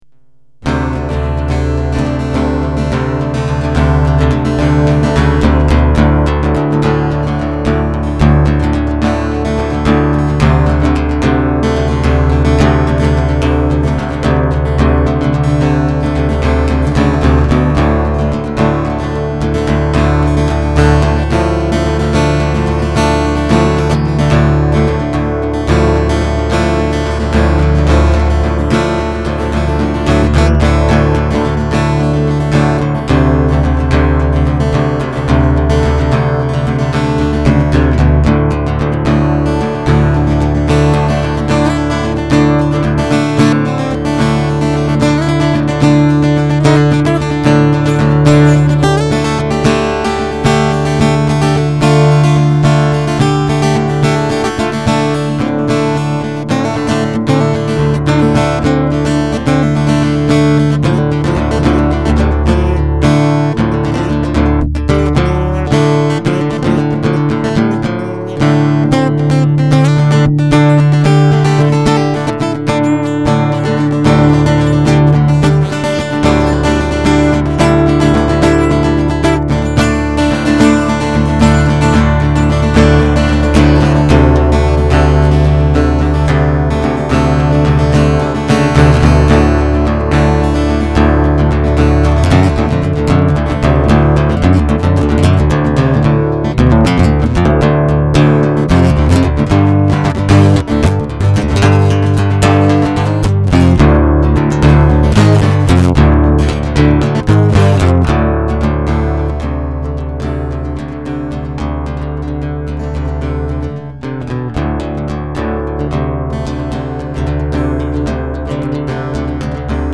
• All acoustic, one guitar.
Most of this is improvised.